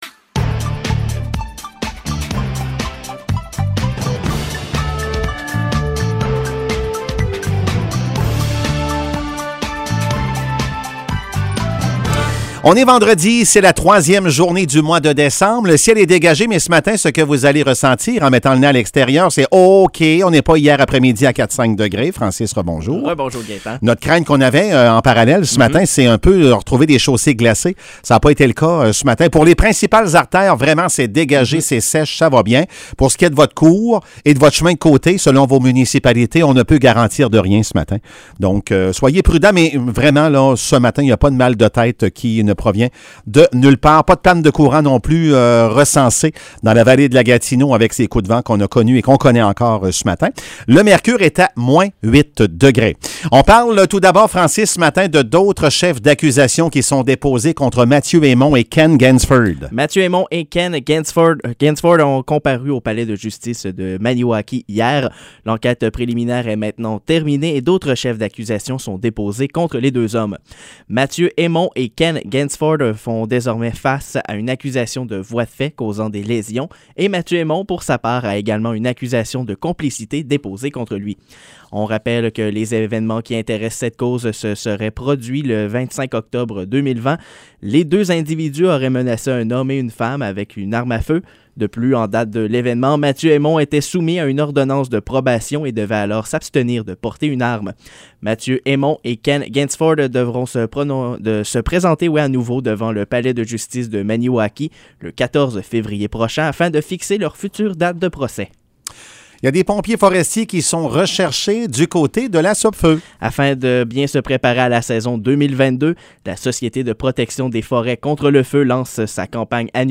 Nouvelles locales - 3 décembre 2021 - 7 h